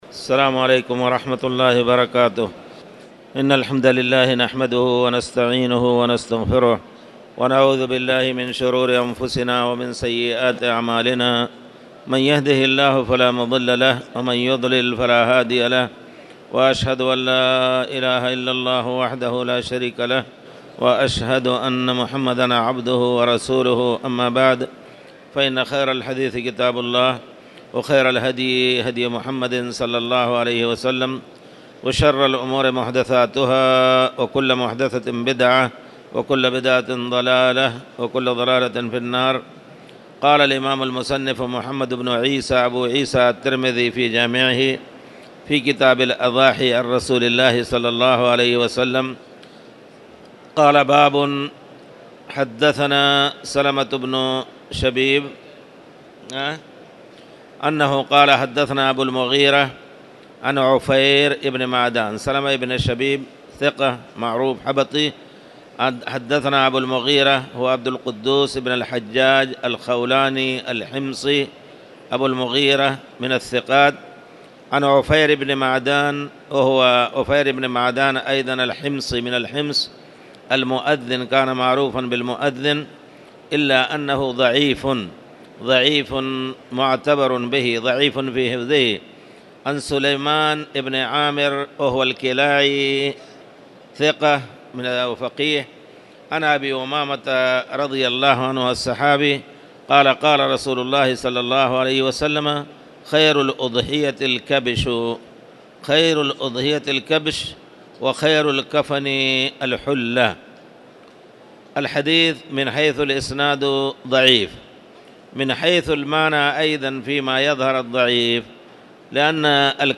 تاريخ النشر ٢٤ جمادى الآخرة ١٤٣٨ هـ المكان: المسجد الحرام الشيخ